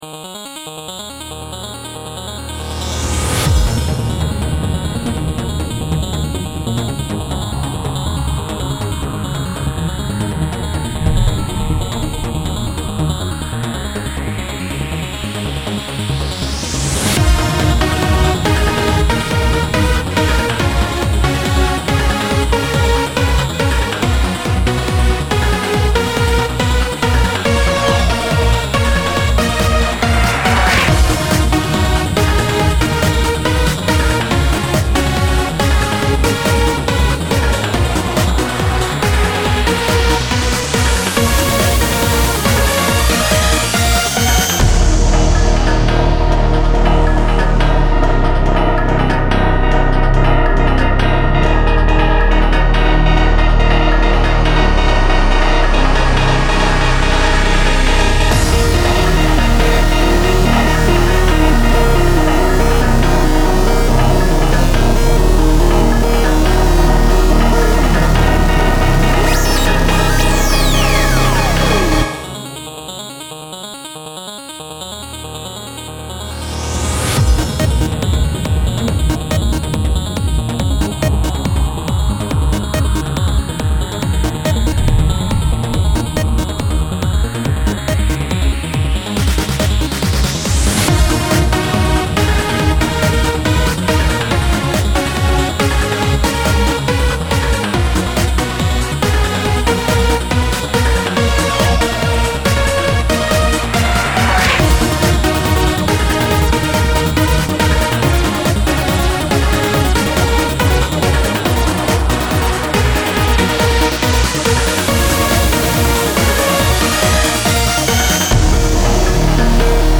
音楽素材ダウンロード　エレクトロ